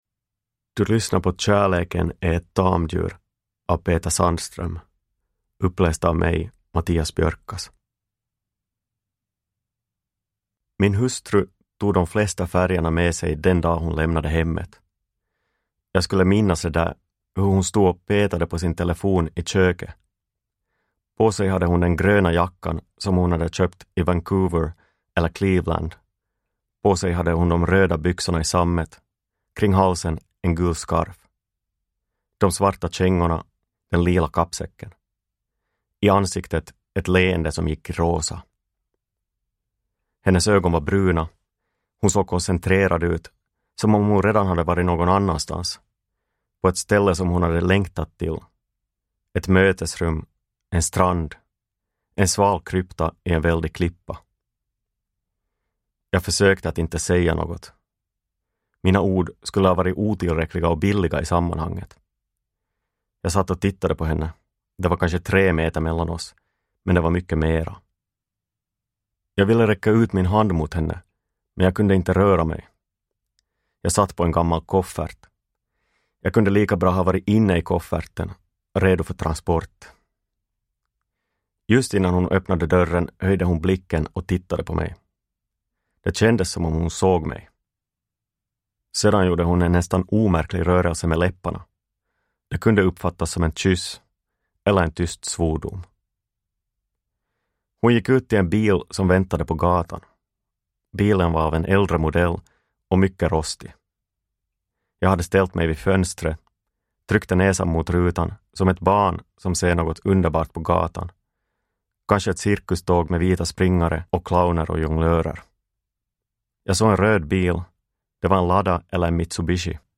Kärleken är ett tamdjur – Ljudbok – Laddas ner